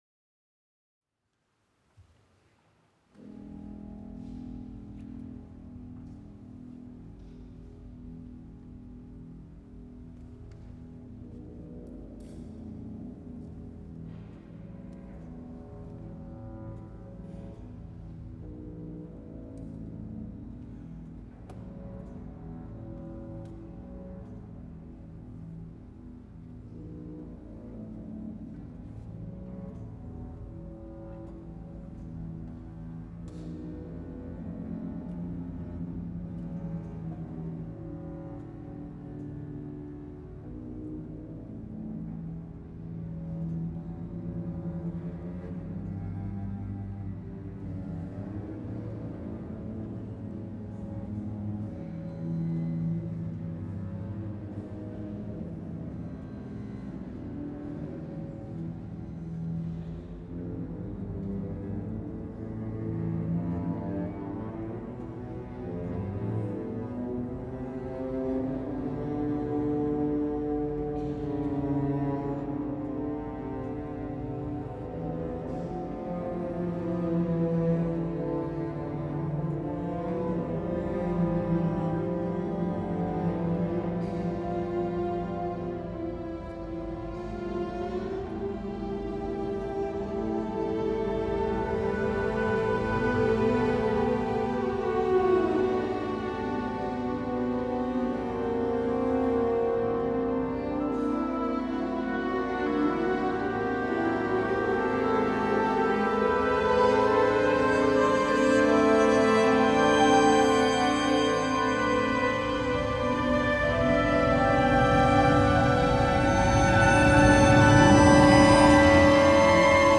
mysterious and beckoning, familiar but dangerous